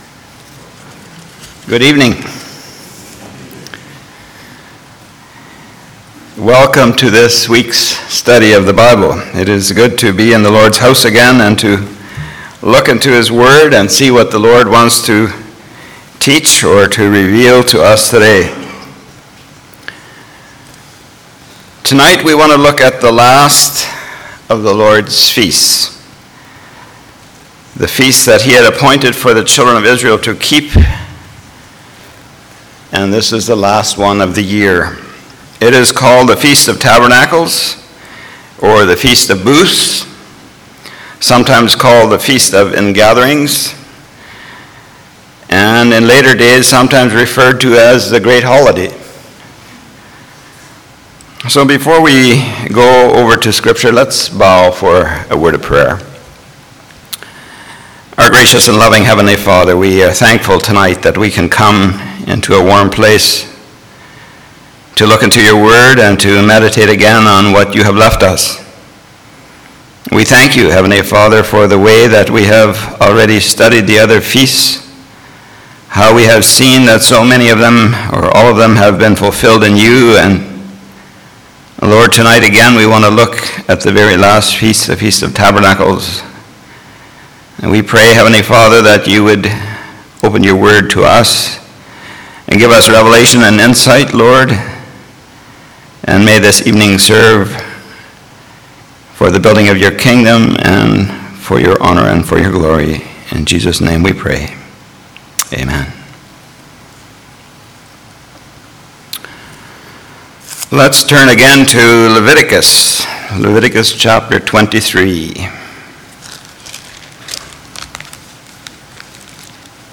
Lev 23:34 Service Type: Church Bible Study « Beware of False Prophets Church Bible Study